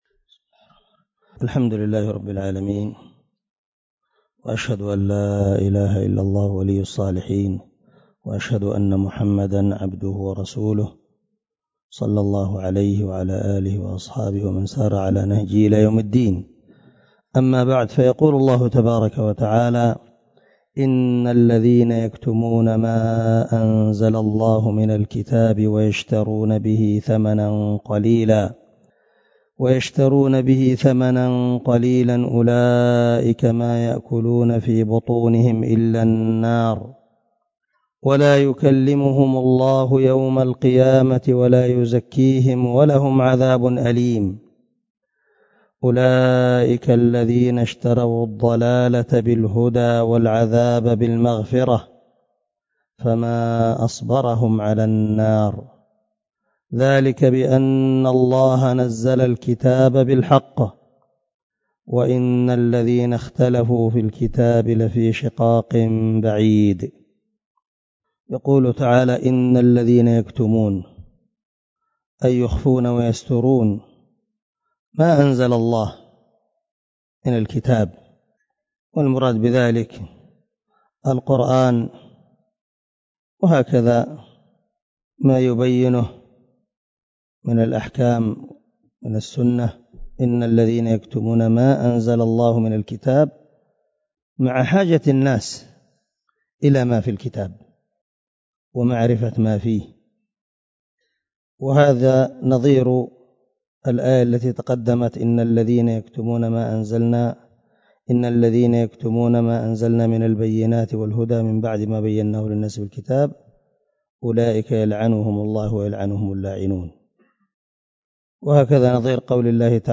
075الدرس 65 تفسير آية ( 174 – 176 ) من سورة البقرة من تفسير القران الكريم مع قراءة لتفسير السعدي
دار الحديث- المَحاوِلة- الصبيحة.